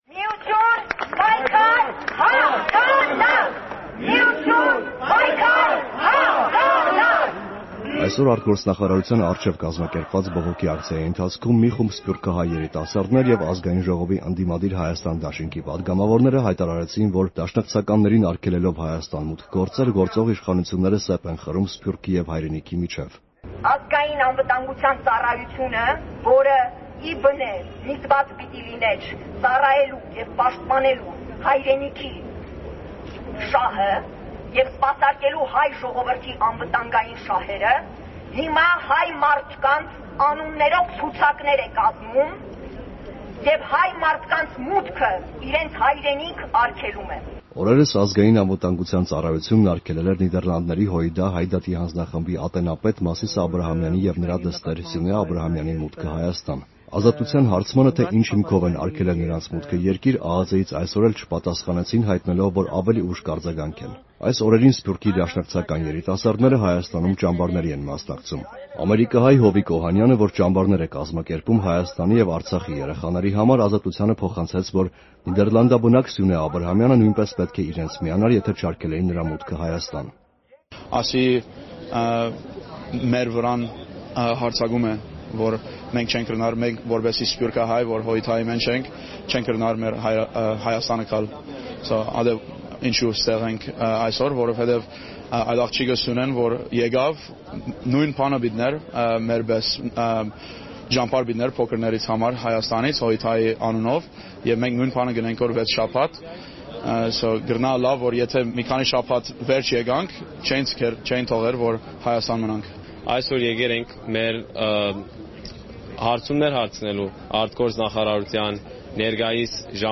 Ռեպորտաժներ
Բողոքի ակցիա ԱԳՆ-ի մոտ` ՀՅԴ անդամների մուտքը Հայաստան արգելելու պատճառով